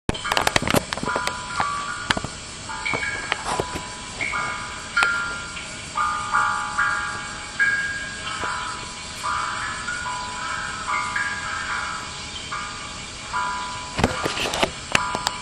Suikinkutsu.WMA